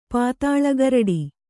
♪ pātāḷa garaḍi